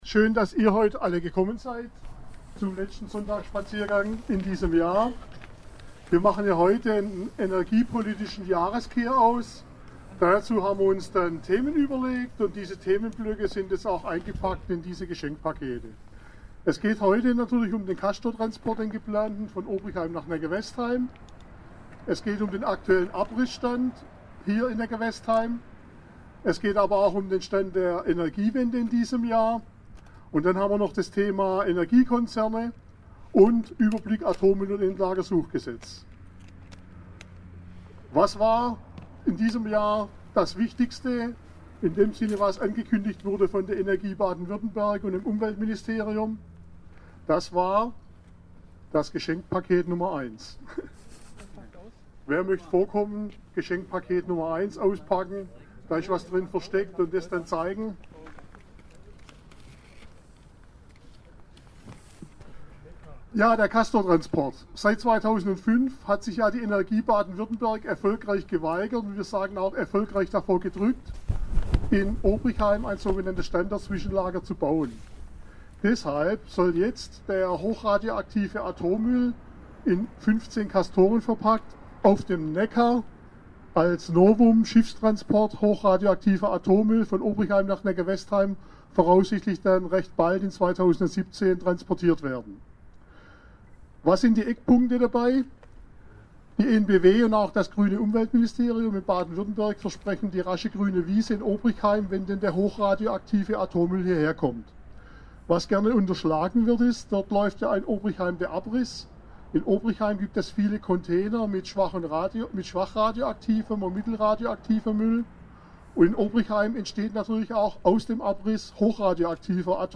In der "Sonntagsrede" wurden zusätzlich Themenstationen des Jahres wie Atommüll & Endlagersuchgesetz, AKW-Abriss, Geschäftsgebaren der Energiekonzerne und last, not least der aktuelle Stand der Energiewende vorgestellt. Im Artikel der Hauptredebeitrag zum Nachhören sowie einige Fotos.